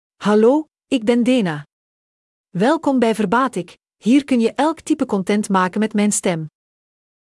Dena — Female Dutch (Belgium) AI Voice | TTS, Voice Cloning & Video | Verbatik AI
Dena is a female AI voice for Dutch (Belgium).
Voice sample
Listen to Dena's female Dutch voice.
Dena delivers clear pronunciation with authentic Belgium Dutch intonation, making your content sound professionally produced.